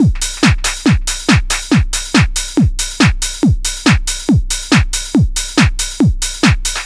NRG 4 On The Floor 016.wav